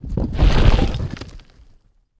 attack.ogg